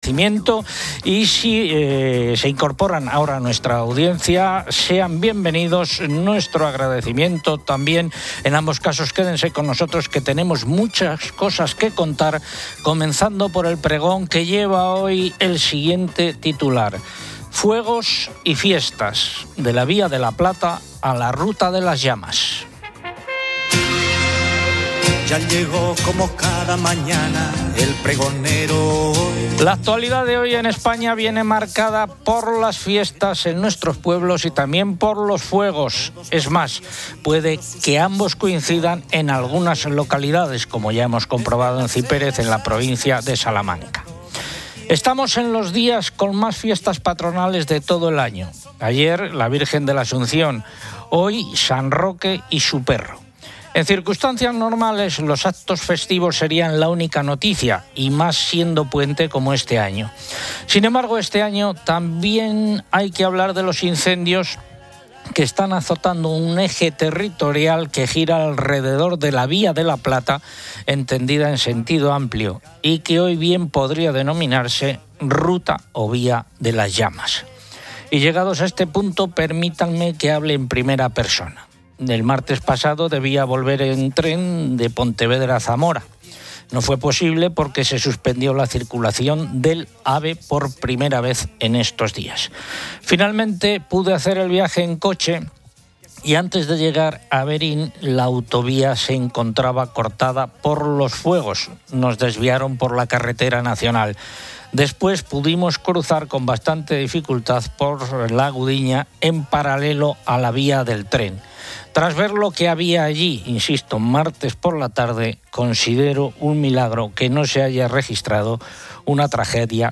El presidente de Castilla y León, Alfonso Fernández Mañueco, es entrevistado.